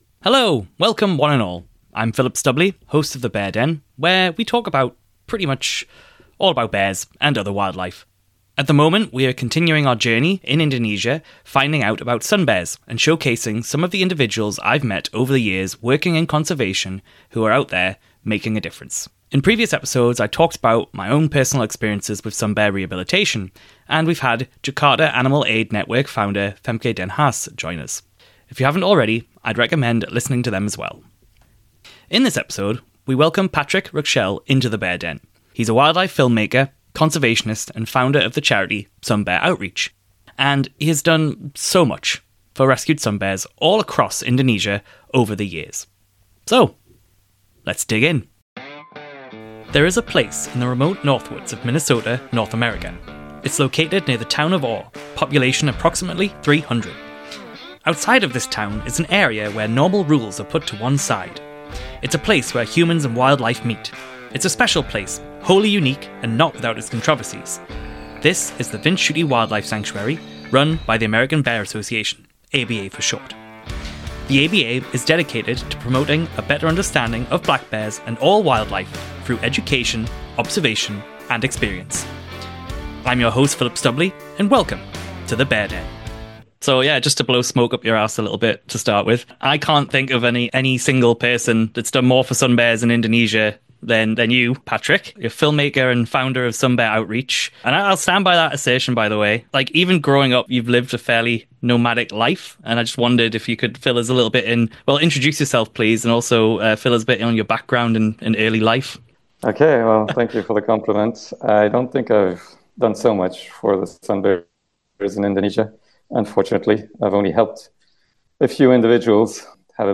Life Is One: A chat